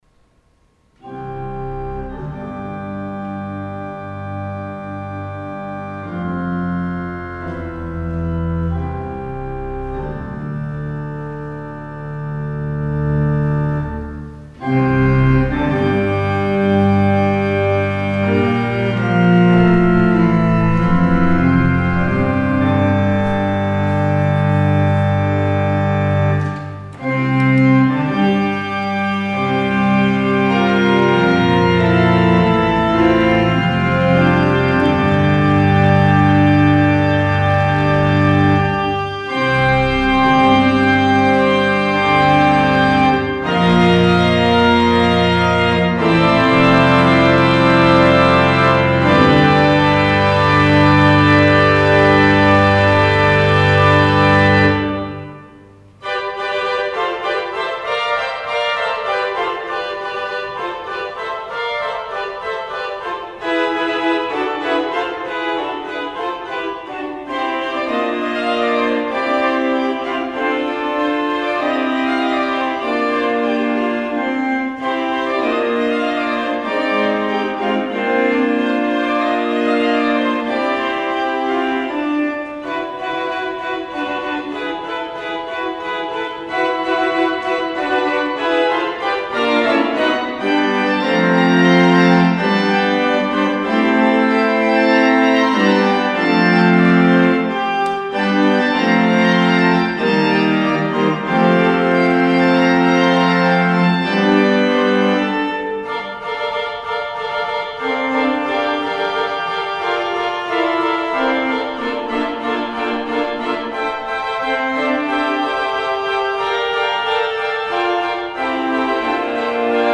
Sunday Worship 7-26-20 (Eighth Sunday After Pentecost)